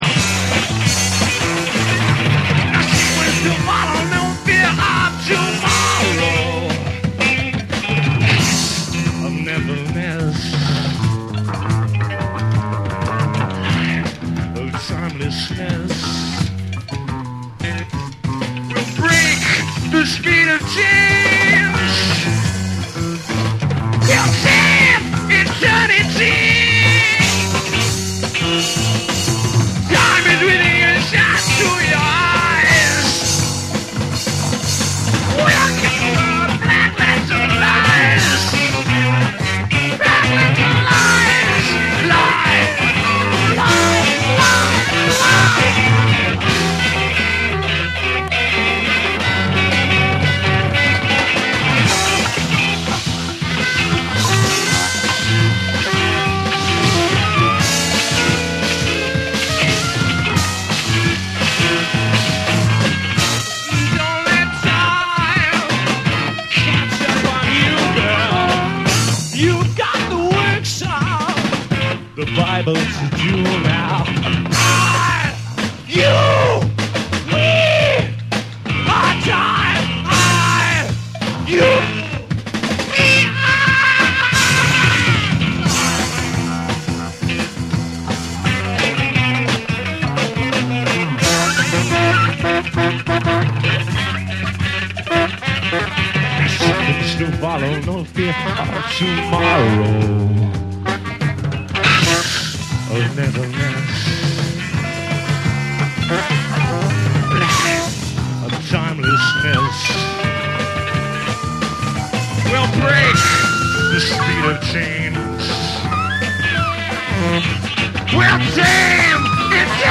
NEW WAVE & ROCK / REGGAE & DUB